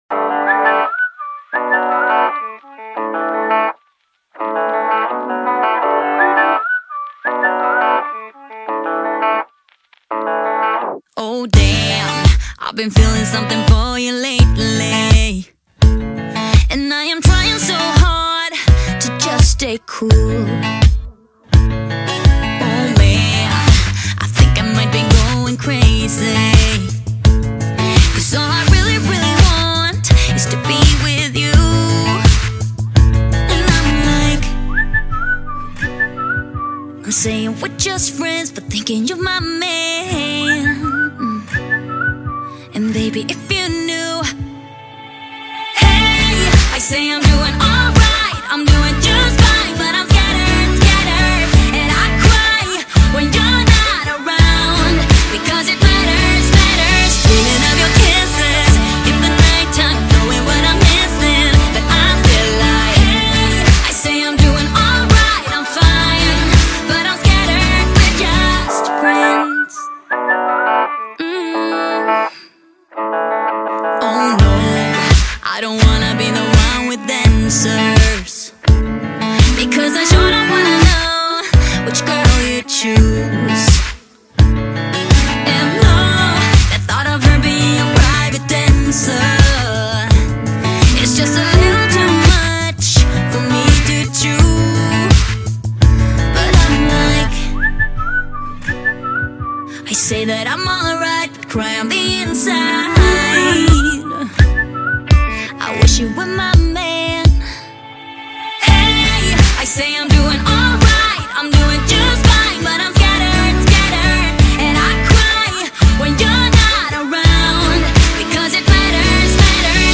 Background Sounds, Programming Soundscapes, Coding Beats